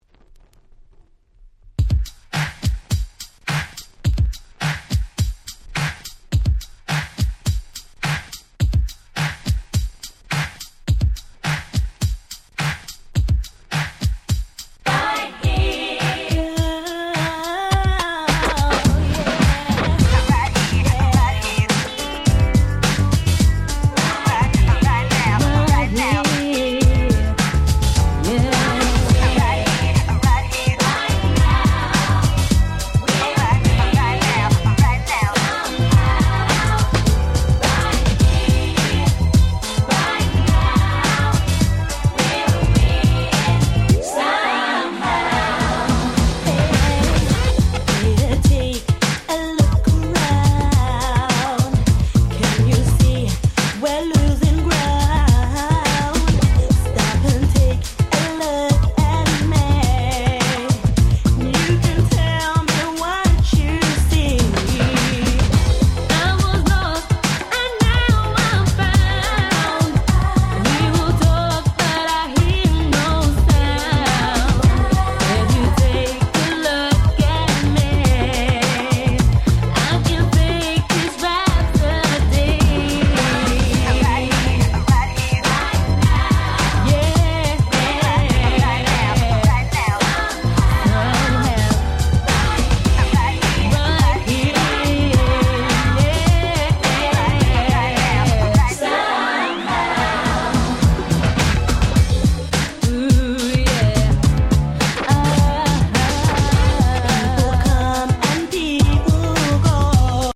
99' Nice EU R&B !!
オリジナルは頭が使いにくくてDJ泣かせでしたが、こちらはBeatから始まりめちゃくちゃ使い易いです！！